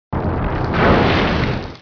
和大家分享一下暴雨素材
炸雷.wav